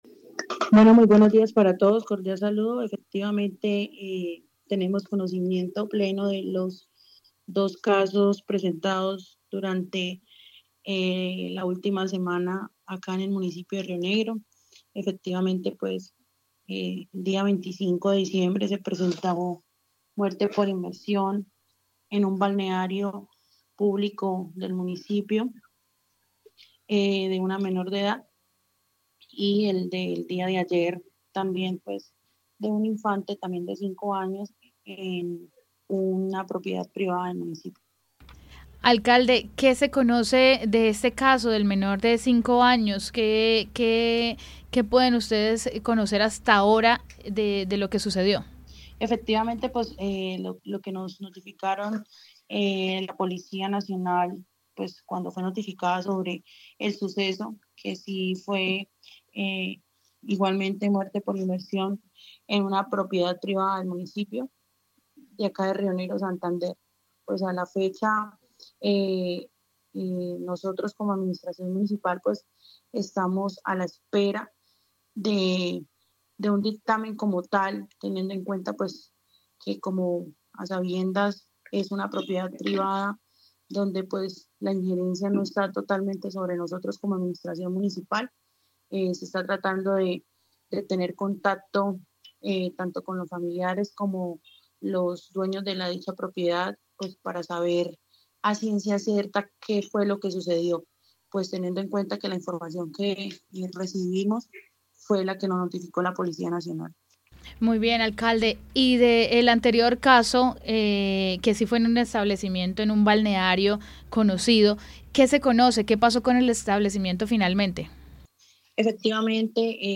Silvia Agon, alcalde (e) de Rionegro, Santander